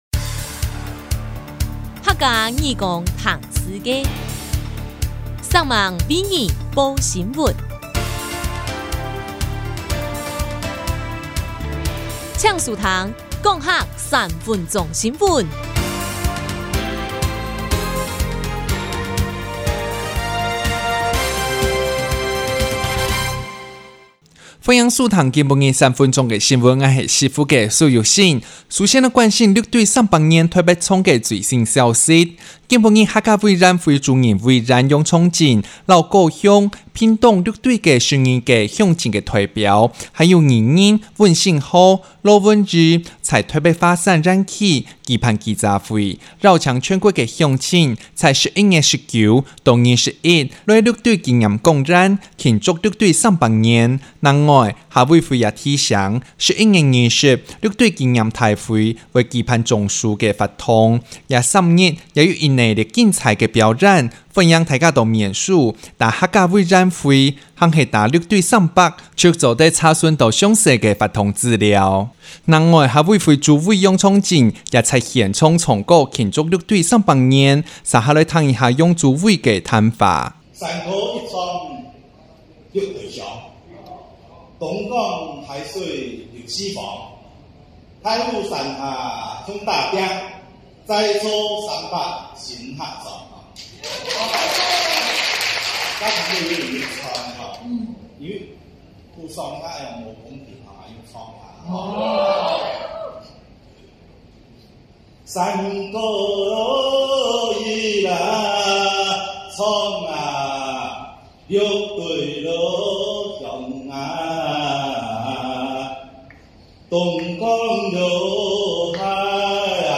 記者會現場以美濃窯陶土蓋手印儀式，宣示手護六堆，同時以接力敲鑼講四句、獻盤花等生動、活潑形式，期許六堆邁向無數個百年，並邀請全國民眾共同來參與六堆300文化慶典。